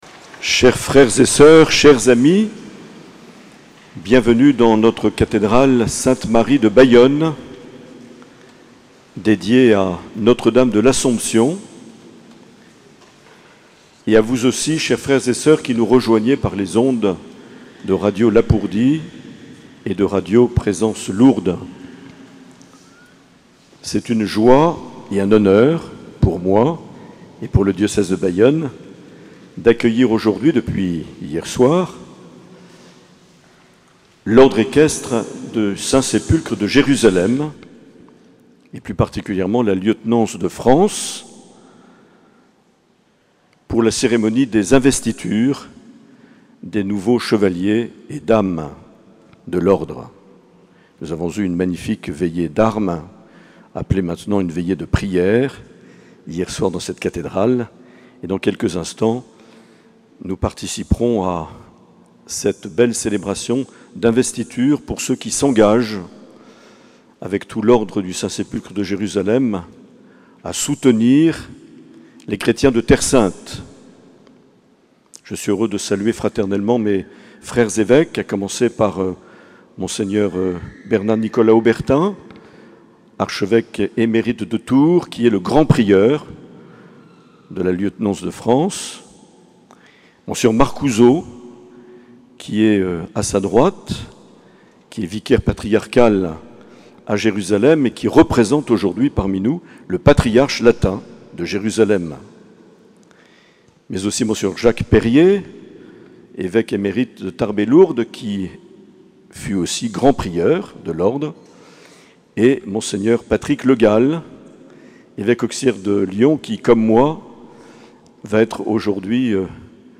25 septembre 2021 – Messe des investitures de l’Ordre équestre du Saint-Sépulcre de Jérusalem - Mot d’accueil de Mgr Aillet